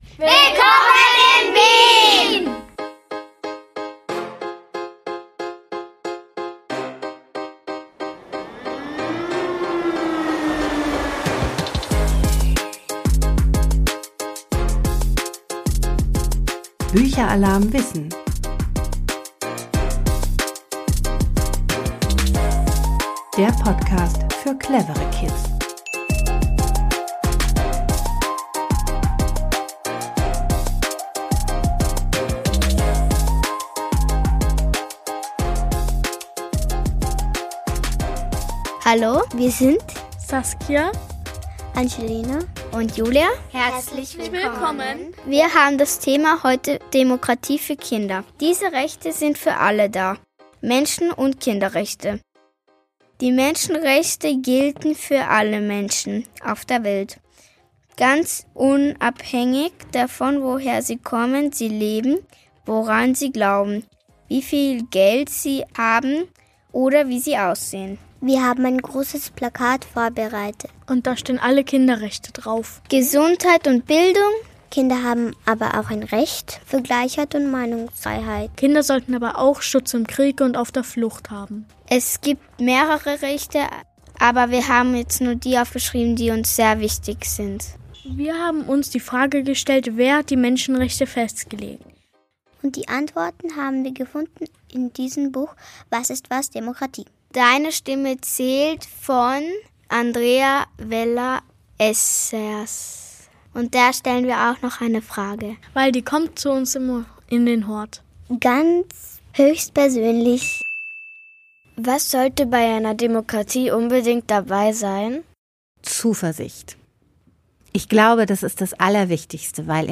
Ein Podcast aus dem KIWI-Hort Hietzing in Wien